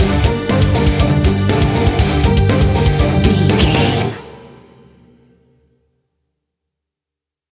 Epic / Action
Fast paced
Aeolian/Minor
hard rock
instrumentals
Heavy Metal Guitars
Metal Drums
Heavy Bass Guitars